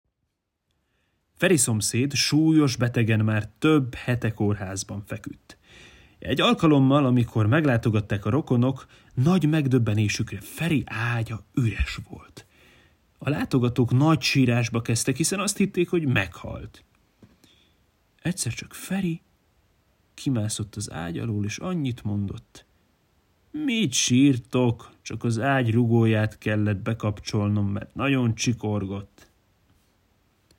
Hazai szerzőket olvasva